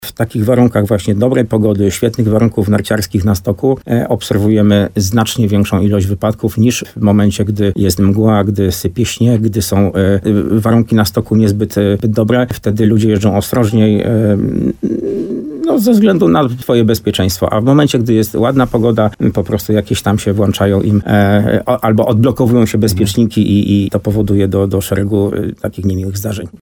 Rozmowa z